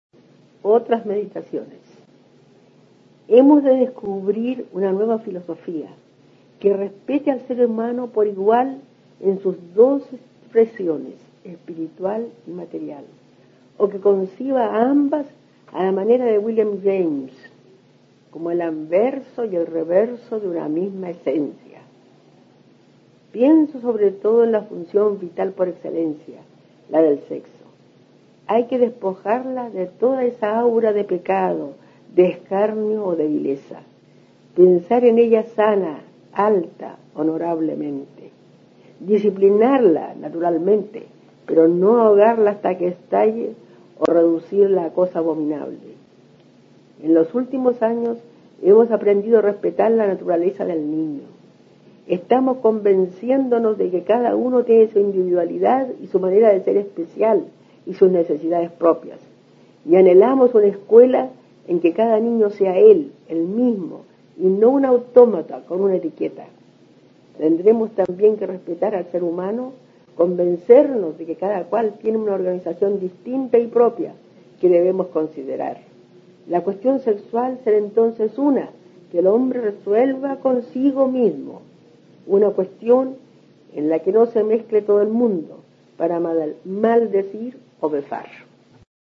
Aquí se puede escuchar a la destacada profesora chilena Amanda Labarca (1886-1975) leyendo su breve ensayo Otras meditaciones, en el que aborda el tema de la sexualidad y el respeto a la libertad de cada individuo para desarrollarse plenamente.